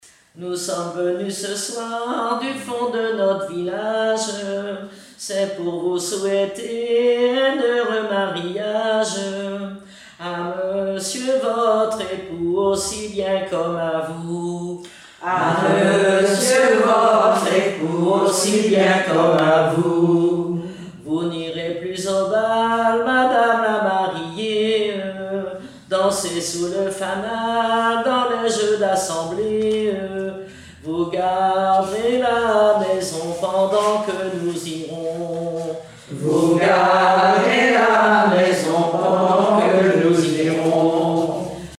circonstance : fiançaille, noce
Genre strophique
chansons et témoignages parlés
Pièce musicale inédite